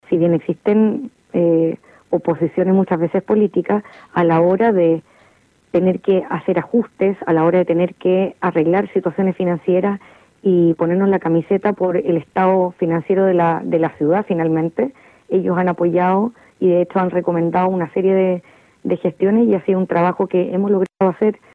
En esa línea, la alcaldesa Macarena Ripamonti destacó que la disminución del déficit se logró mediante la optimización de recursos, la reducción de horas extras y un mayor control del gasto, junto con el aumento de ingresos provenientes de concesiones, nuevos permisos y mejoras en los procesos de recaudación, todo ello trabajado en conjunto con el Concejo Municipal.